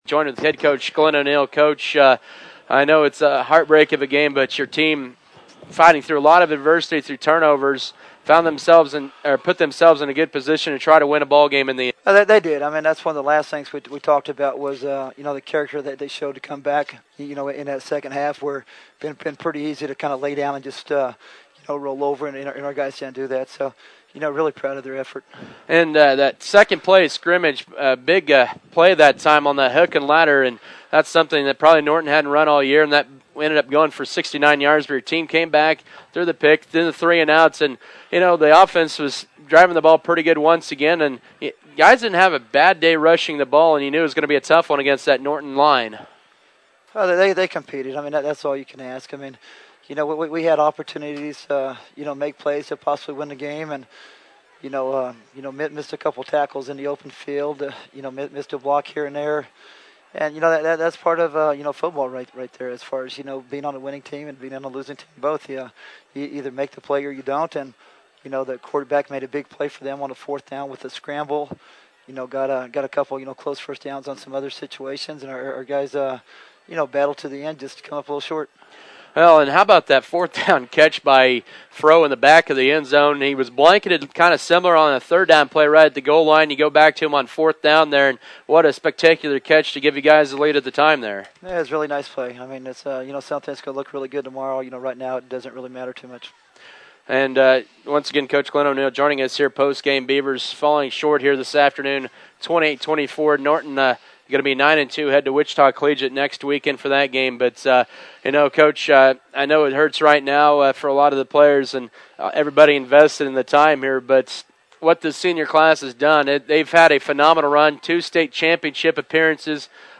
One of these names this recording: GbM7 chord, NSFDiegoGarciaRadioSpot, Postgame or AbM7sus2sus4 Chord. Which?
Postgame